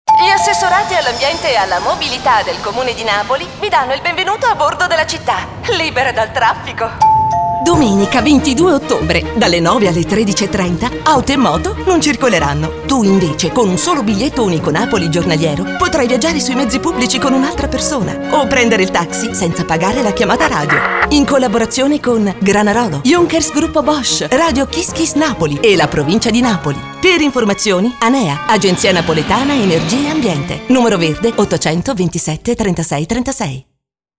Spot 22 Ottobre.mp3